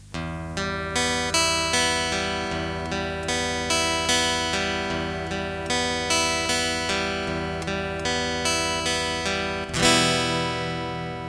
Afin de montrer l'existence d'une différence de timbre, nous avons effectué deux enregistrements
guitare 2